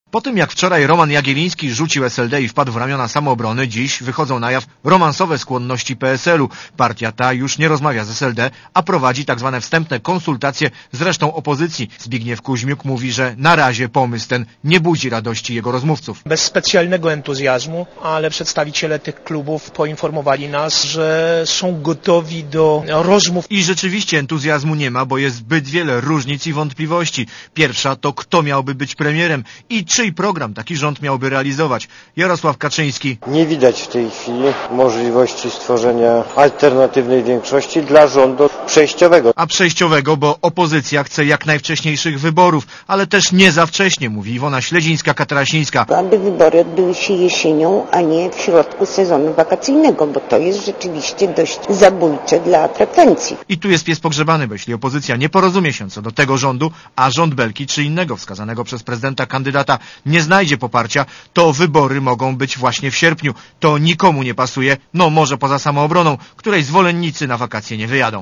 W Sejmie jest reporter Radia ZET